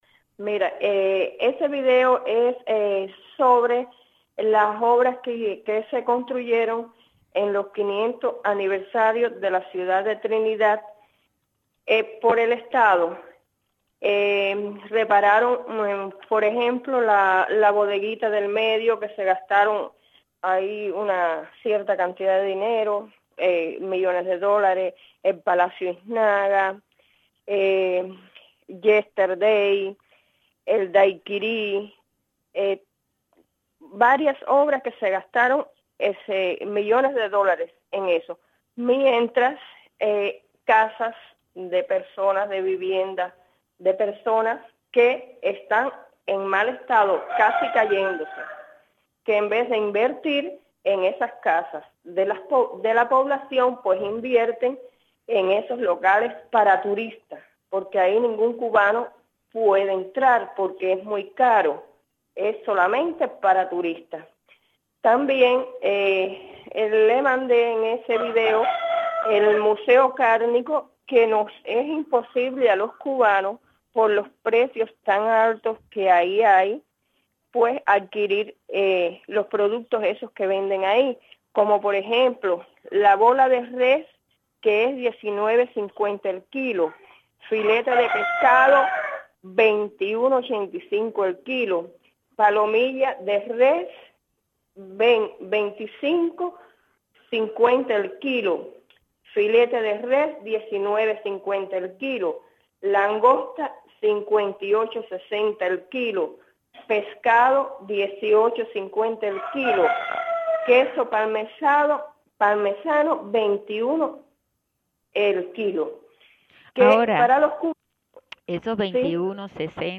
Reportera ciudadana